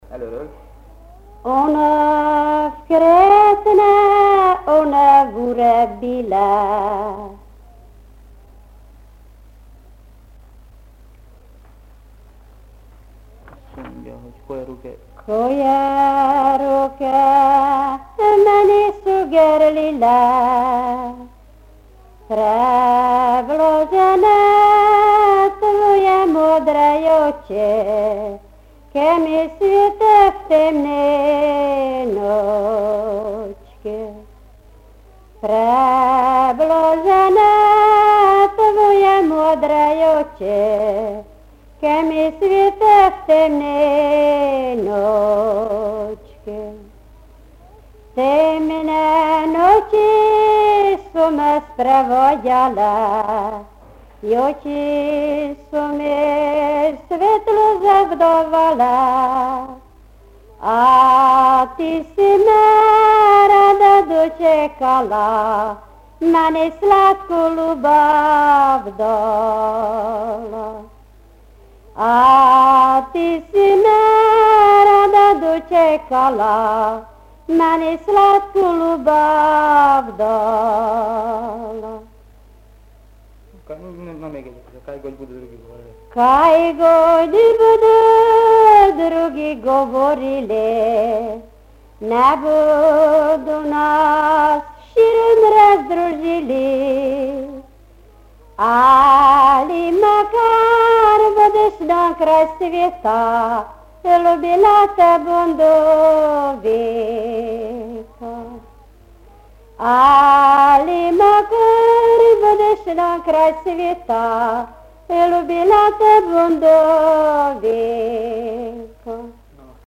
Dialect: B
Locality: Tótszentmárton/Sumarton
The melodies in pentatonic scale show a high level of similarity with Hungarian traditional songs, but also some characteristics of the Croatian tradition, which was pointed out by Tihomir Vujičić (see the number Zbogom pole, gore… in the following order of melodies).